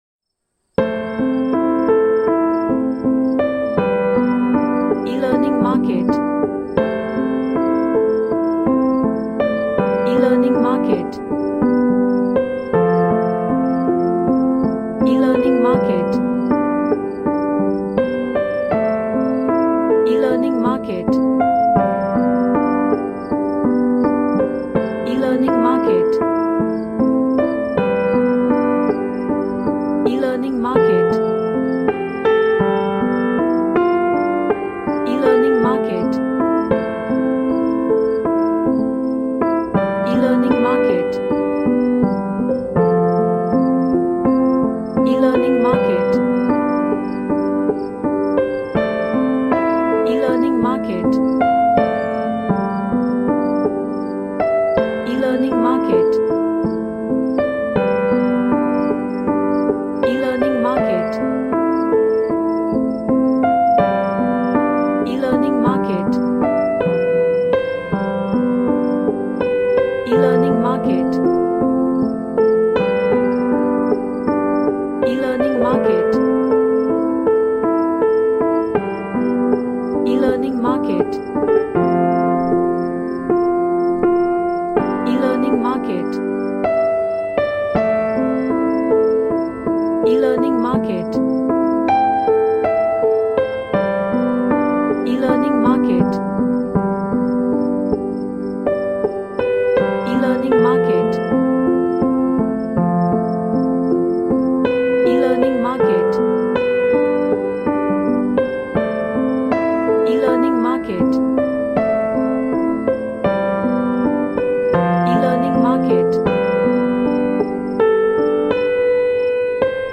A emotional piano music
Emotional